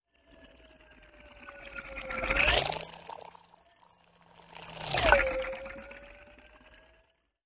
嗖嗖嗖" 嗖嗖嗖进出 3
Tag: 空气 旋风 传递由